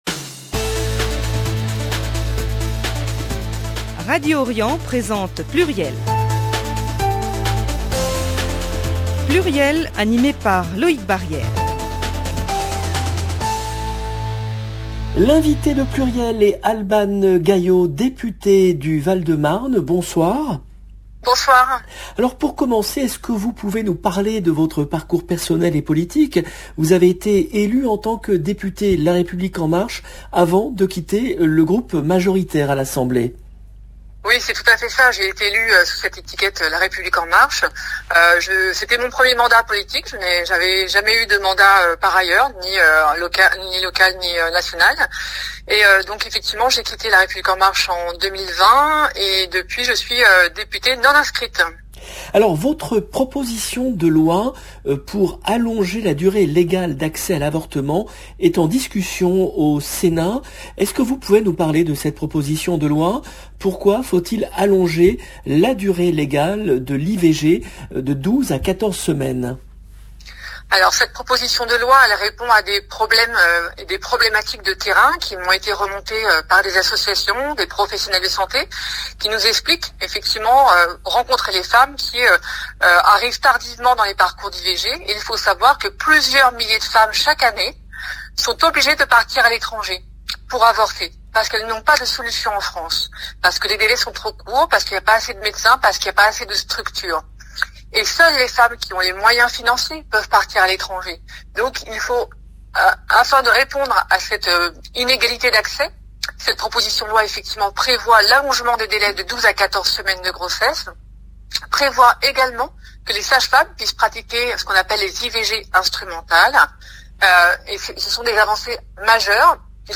Albane Gaillot, députée du Val-de-Marne
L’invitée de PLURIEL est Albane Gaillot , députée du Val-de-Marne. Parmi les sujets évoques lors de cette émission, la proposition de loi d'Albane Gaillot pour allonger la durée légale d’accès à l’avortement.